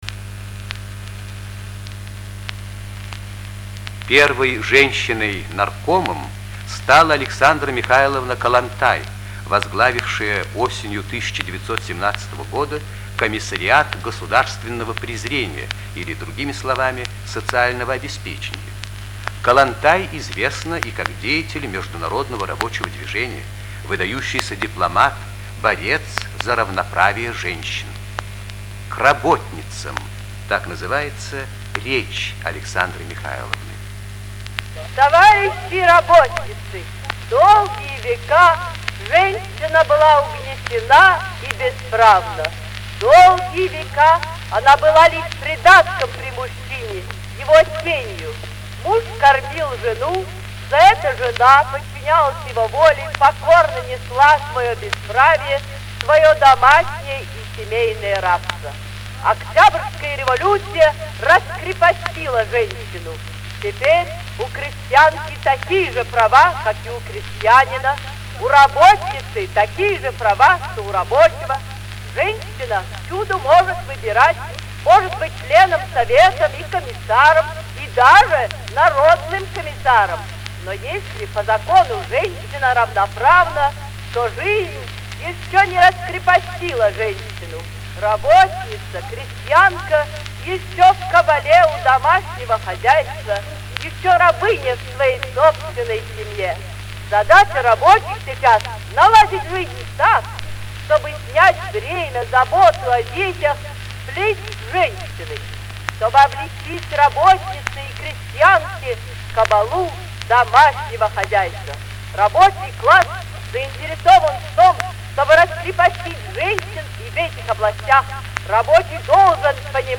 Голос Александры Михайловны Коллонтай